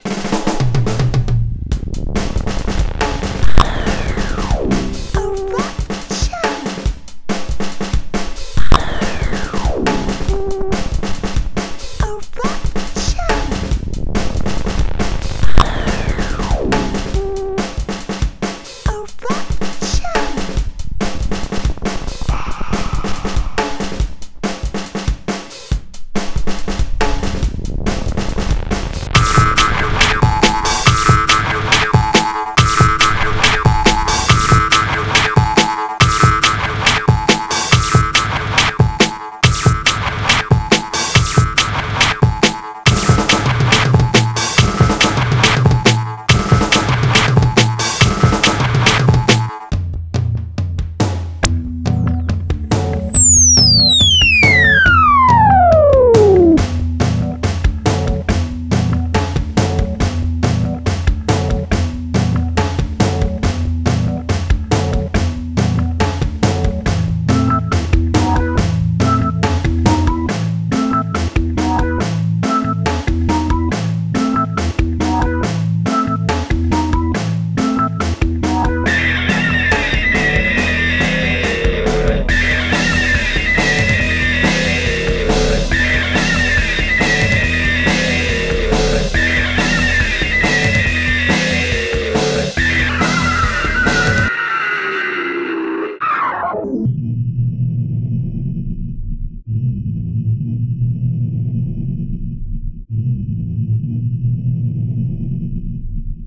guitarra_1 bajo bateraa_1 bien noisepopsurf